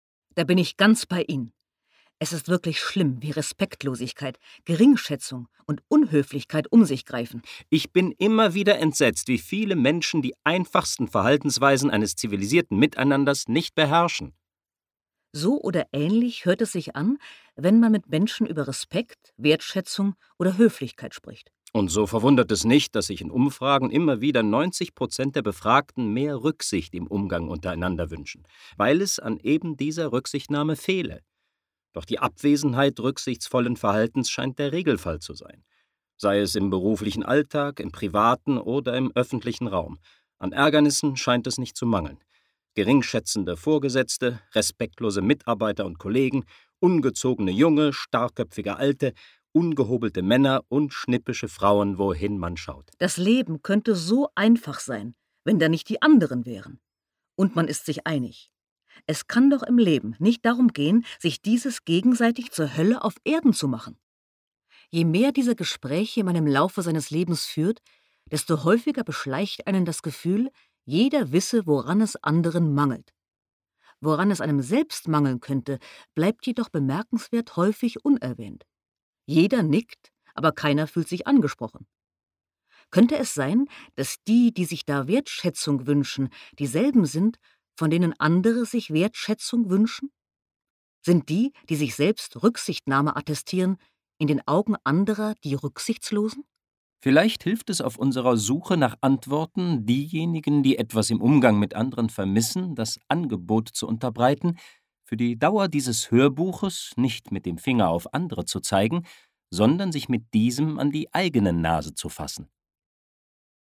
Wie man den Wolfspelz ablegen kann, darüber handelt dieses Hörbuch.